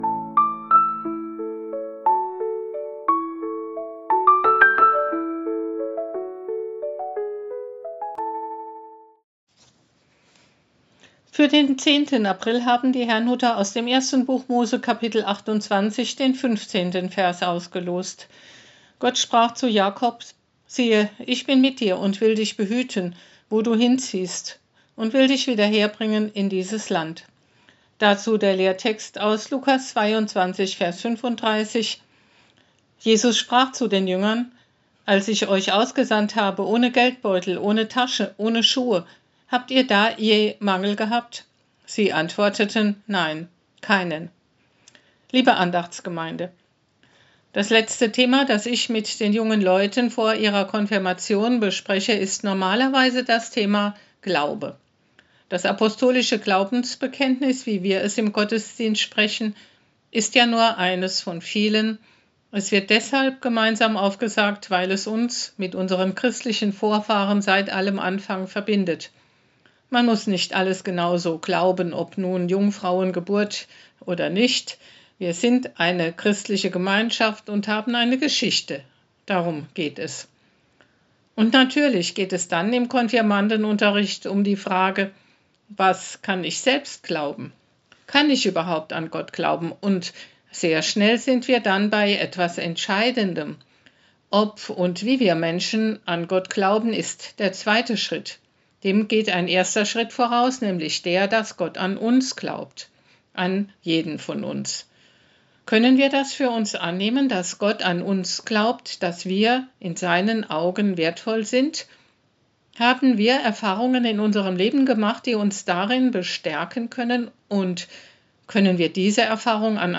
Losungsandacht für Freitag, 10.04.2026